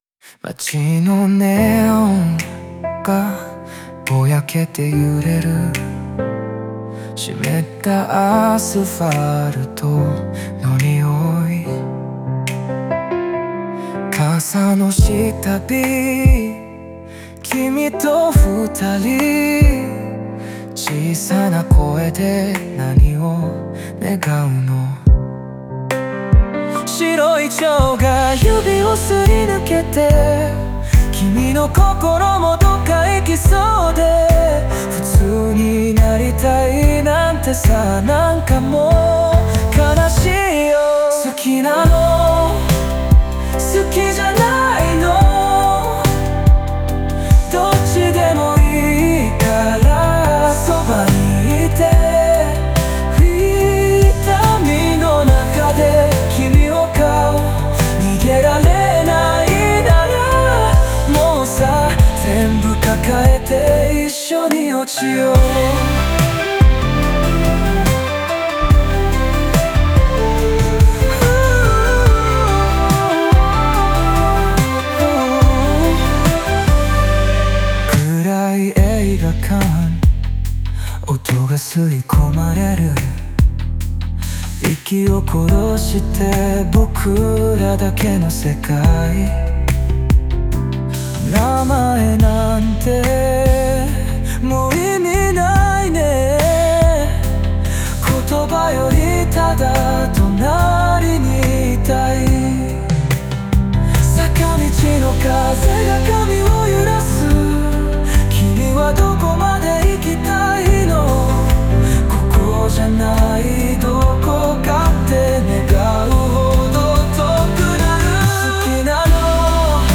話し言葉を使い、リアルな感情が伝わるようにしました。